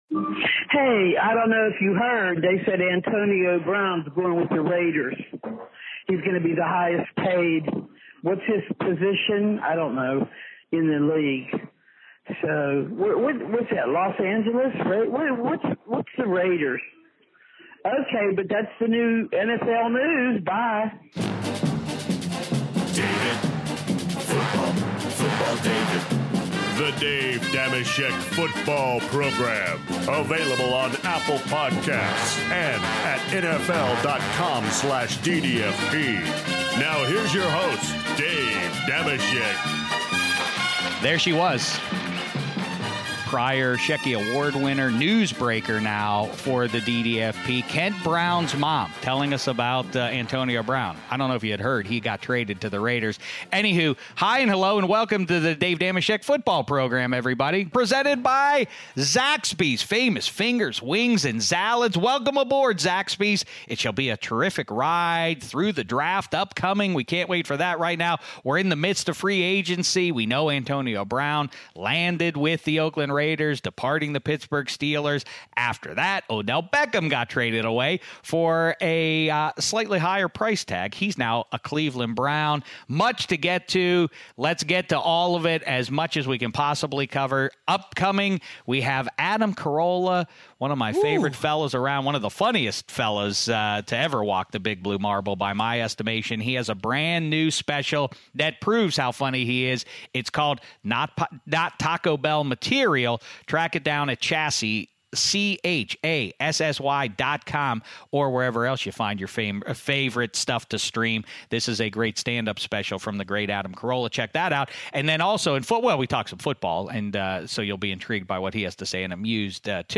Dave Dameshek is joined in Studio 66
Next up, Shaun O'Hara drops by Studio 66 to chat with Shek about the state of the New York Giants after trading away their superstar OBJ (15:22).